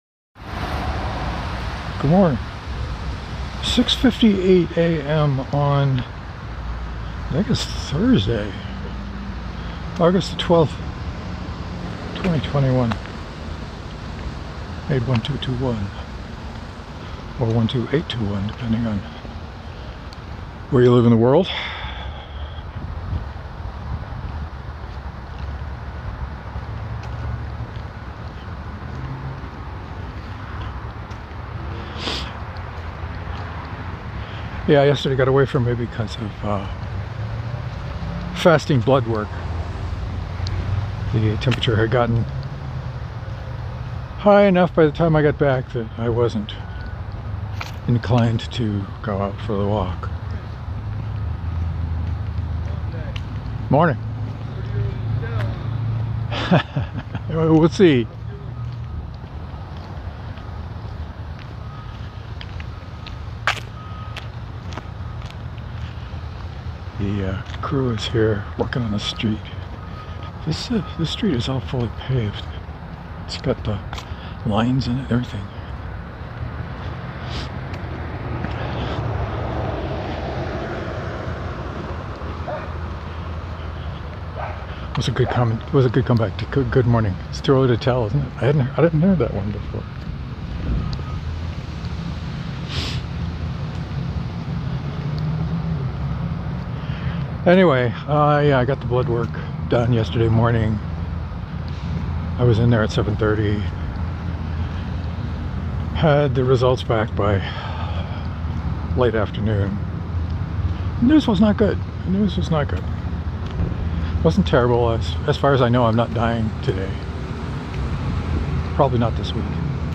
I talked about it today. WARNING: Heavy equipment noise in the beginning and some wind artifacts at the end.